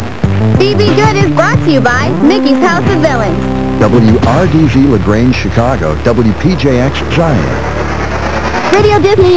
These are sound clips of some of my airchecks.
WRDZ/WPJX Legal ID